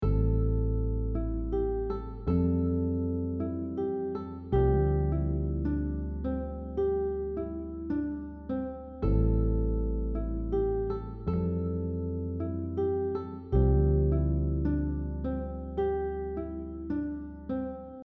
In the second example, we’re still using shape 5, but this time the focus is on creating a musical idea or theme using the scale notes.